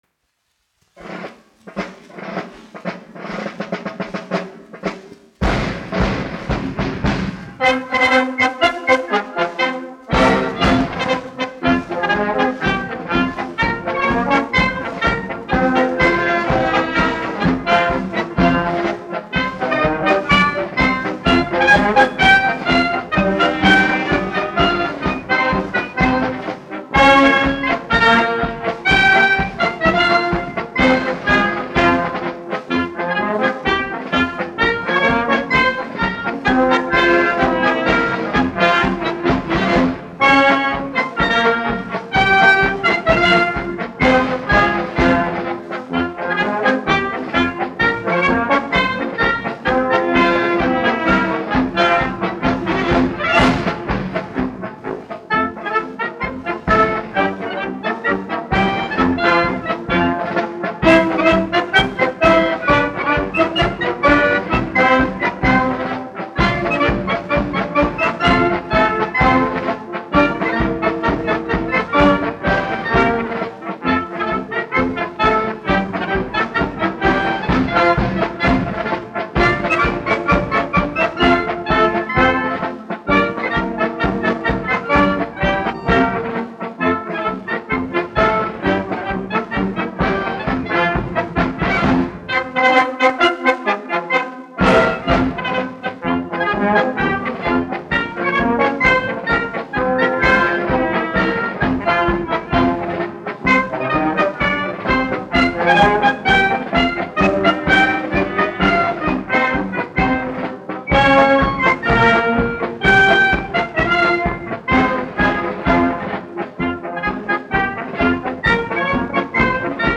1 skpl. : analogs, 78 apgr/min, mono ; 25 cm
Marši
Pūtēju orķestra mūzika
Skaņuplate